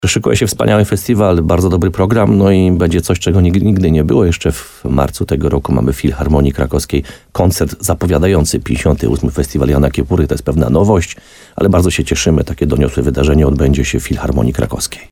Jak mówi burmistrz uzdrowiska, Piotr Ryba, w tegorocznej edycji festiwalu, szykują się też inne nowości.